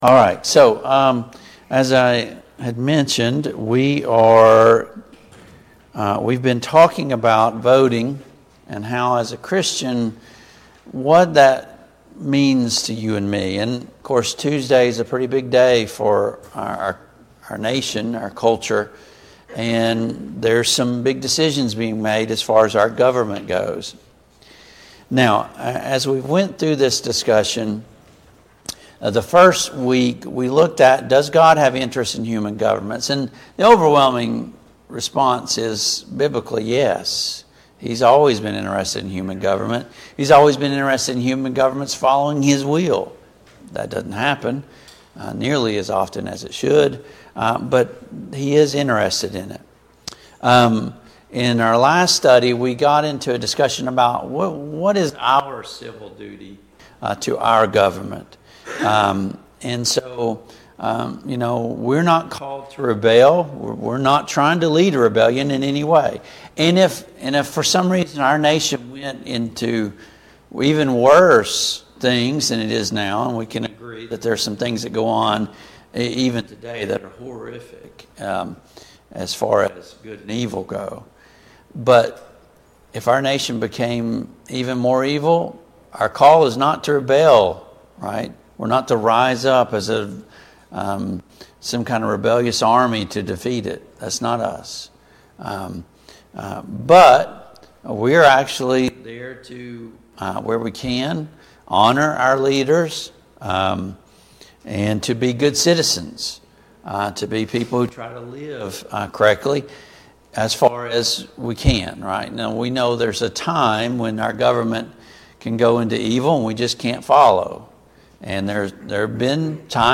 Sunday Morning Bible Class